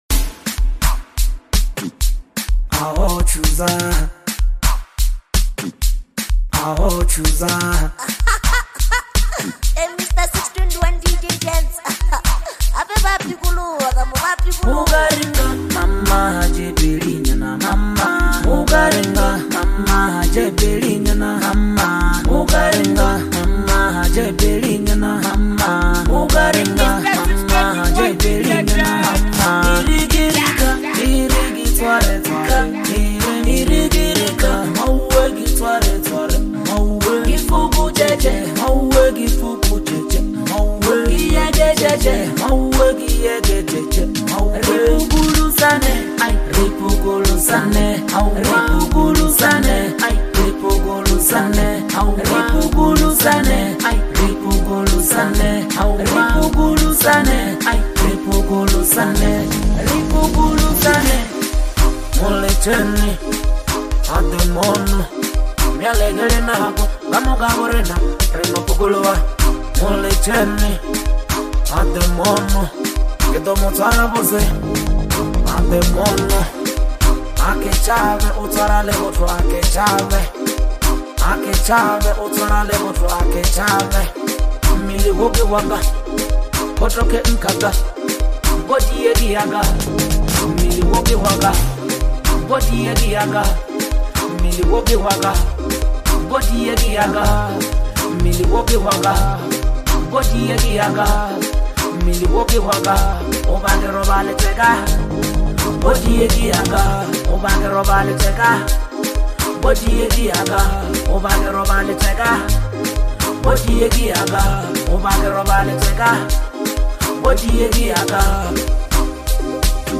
Lekompo
fresh Lekompo banger